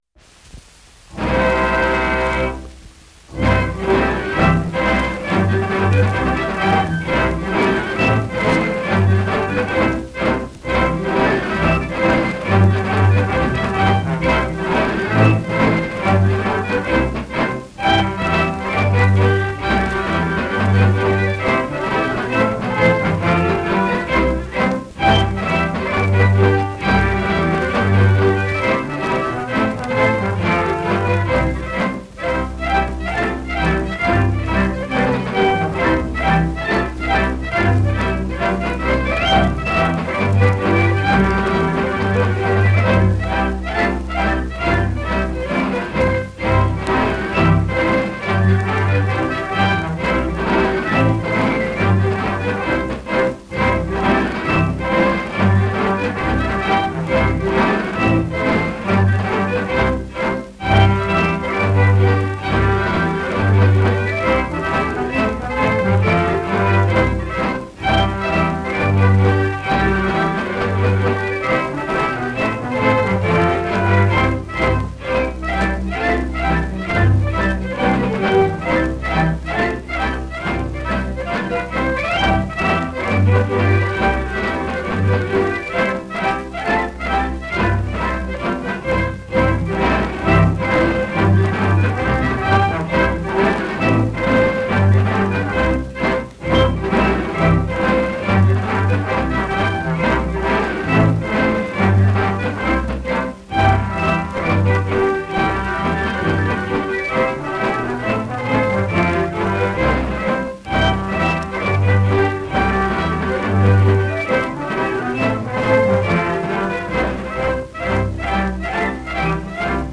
1 10 inch 78rpm shellac disc